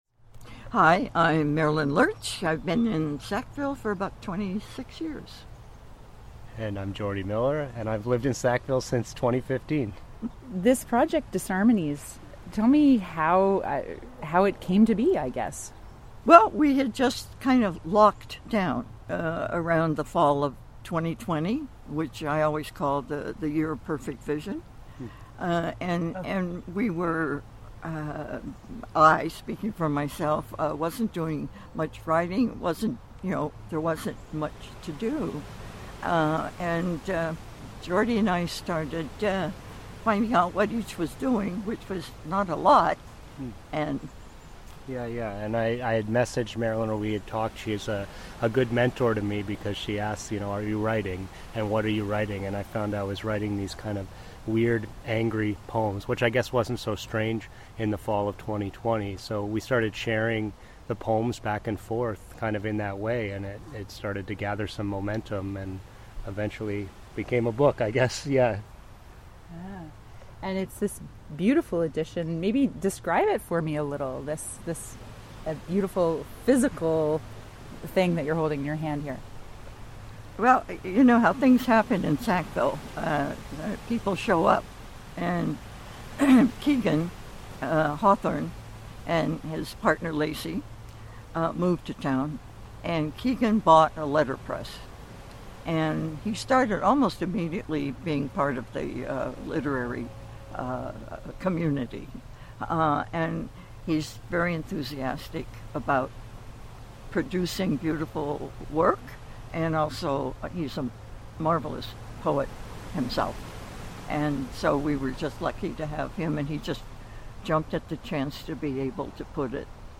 in a shady backyard, with the howling Sackville winds in the background